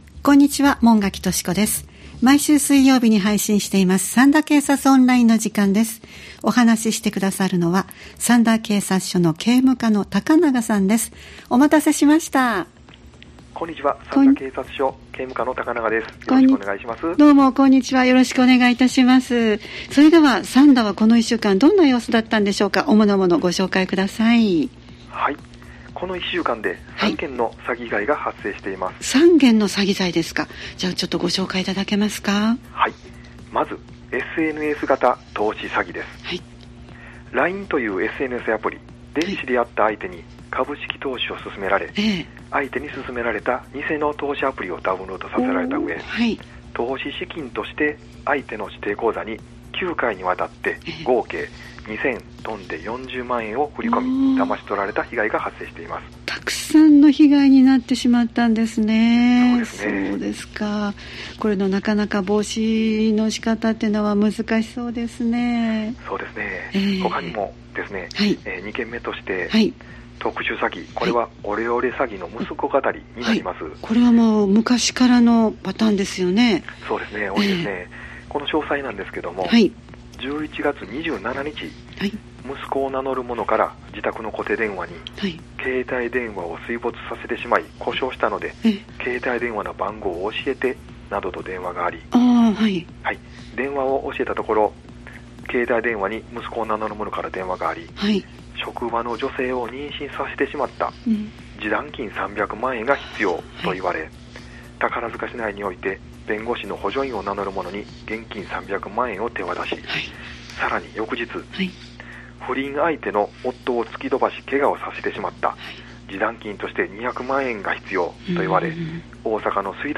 三田警察署に電話を繋ぎ、三田で起きた事件や事故、防犯情報、警察からのお知らせなどをお聞きしています（再生ボタン▶を押すと番組が始まります）